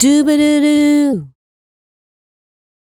Doo Bahdoodoo 085-D.wav